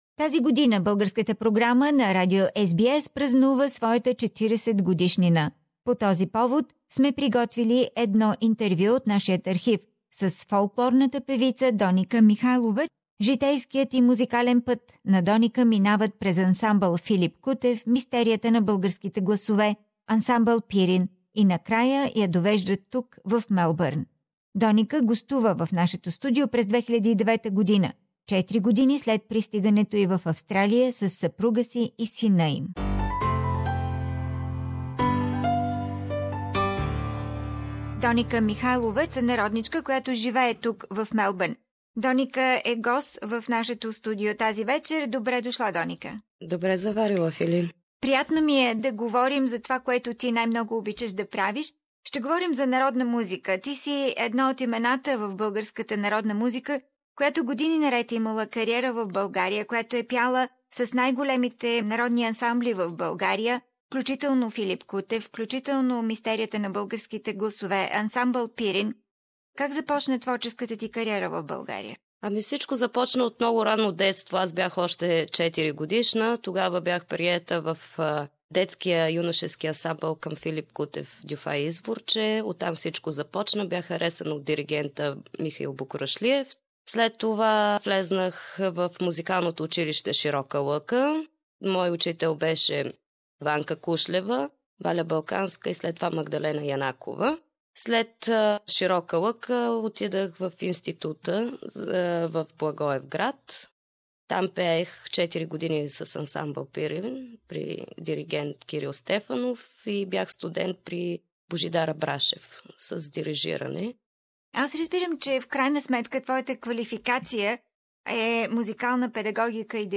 This year the Bulgarian program on SBS radio celebrates its 40th anniversary. Flashback interview